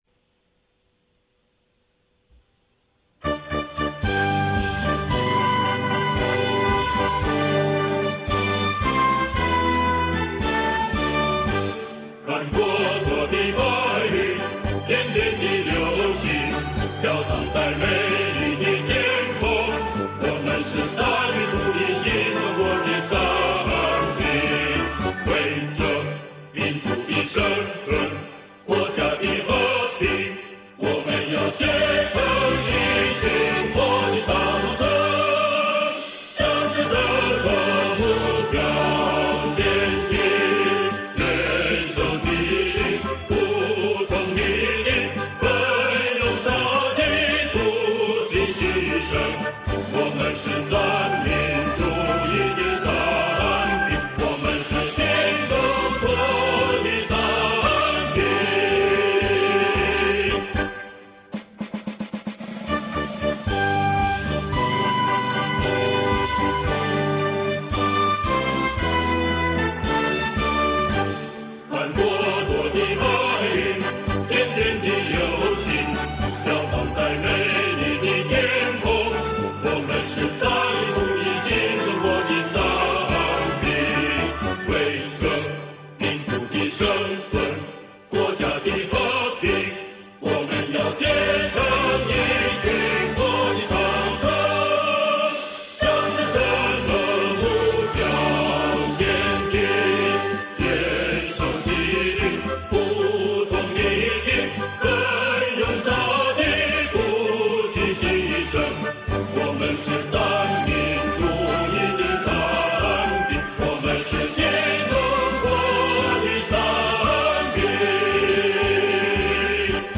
詞曲佚名
歌唱版RA